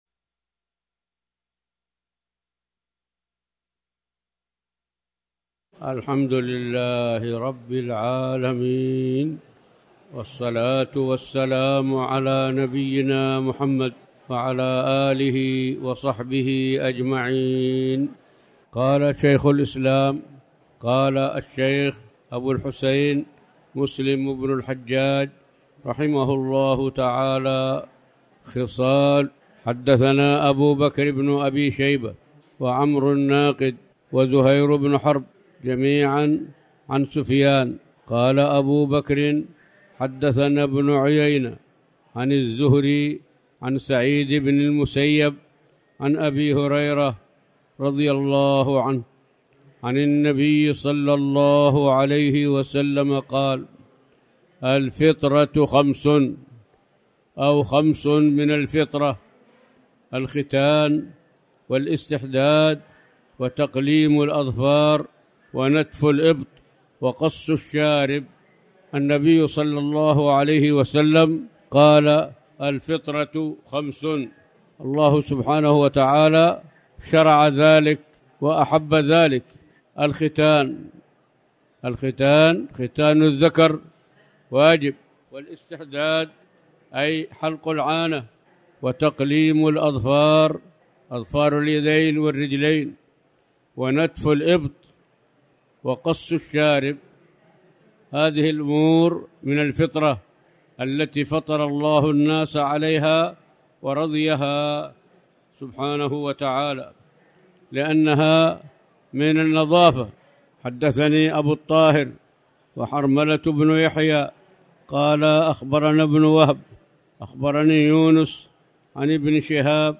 تاريخ النشر ١٨ ربيع الأول ١٤٤٠ هـ المكان: المسجد الحرام الشيخ